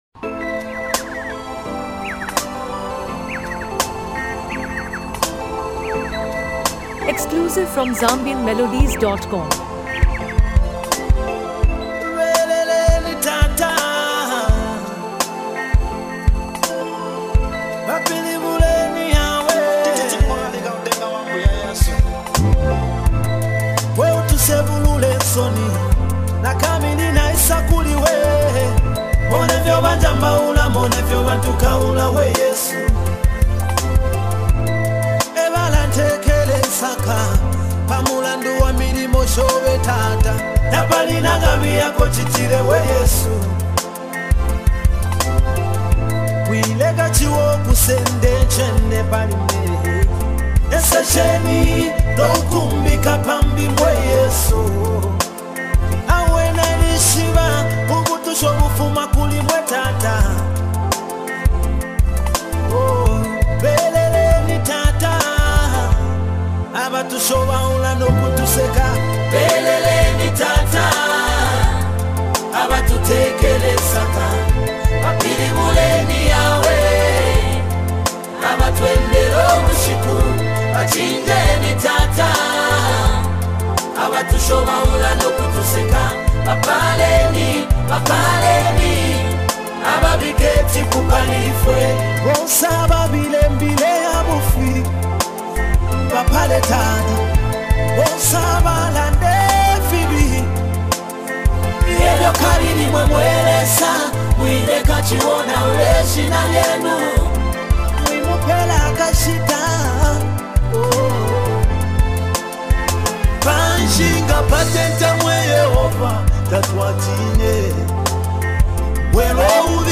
gospel anthem